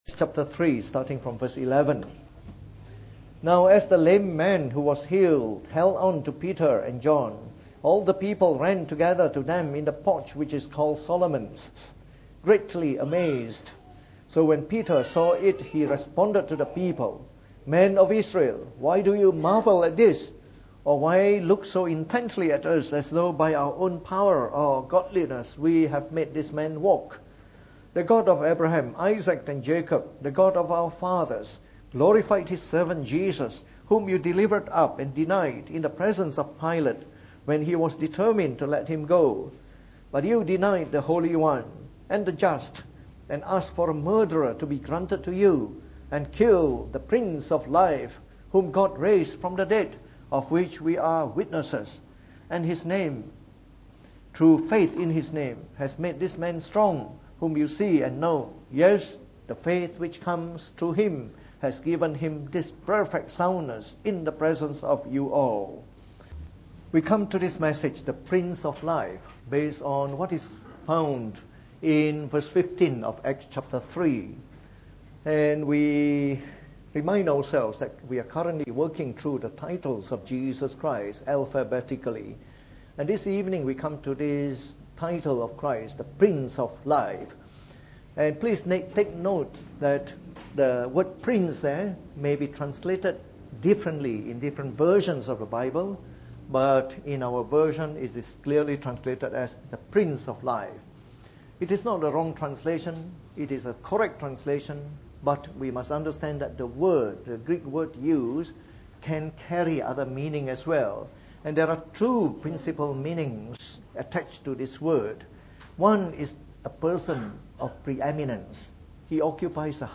Preached on the 29th of April 2018. From our series on the Titles of Jesus Christ delivered in the Evening Service.